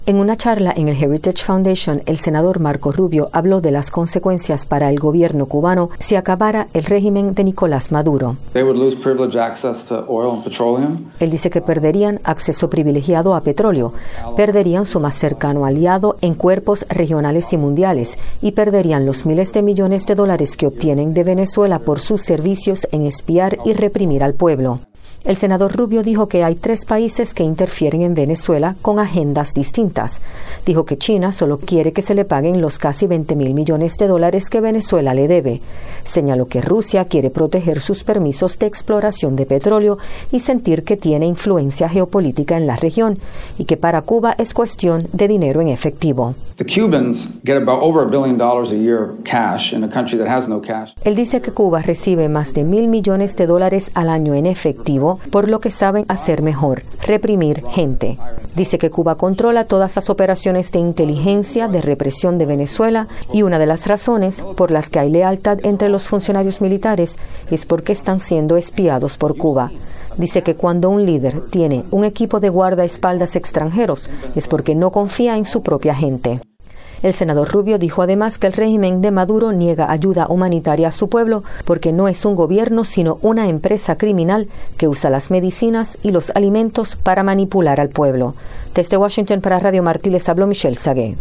El senador estadounidense Marco Rubio dijo este lunes que la caída del régimen de Nicolás Maduro en Venezuela constituiría un fuerte golpe para el gobierno cubano, que perdería a su principal aliado. En una charla en el Heritage Foundation, el senador Rubio habló de las consecuencias para el...